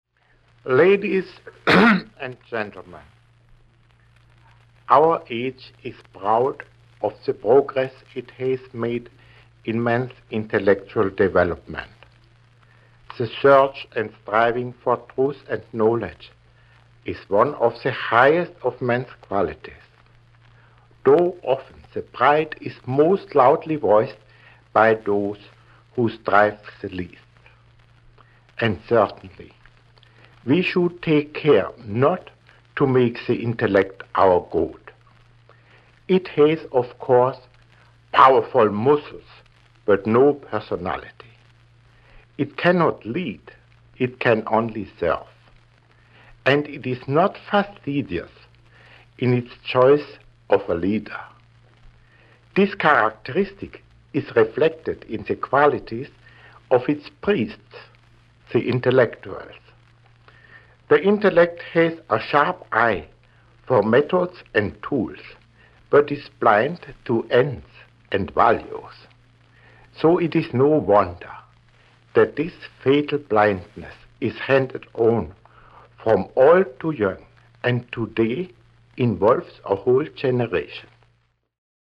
Lydfil av Albert Einstein.